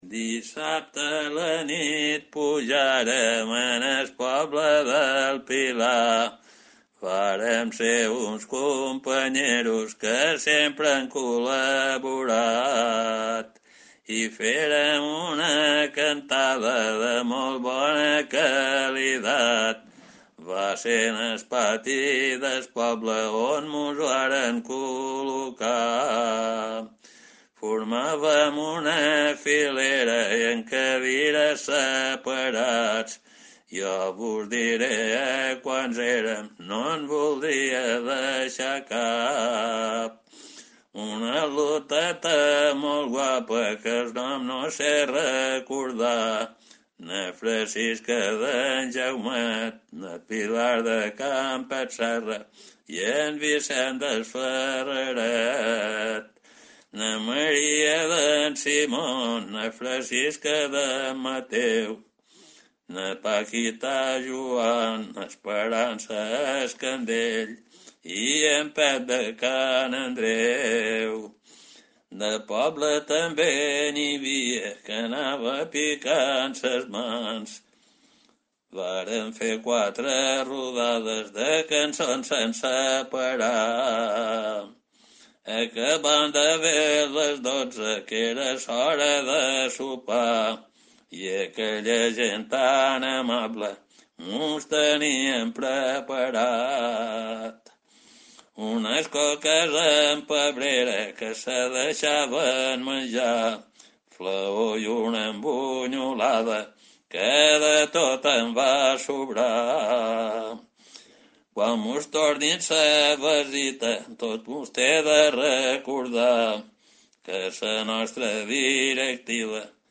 El cantador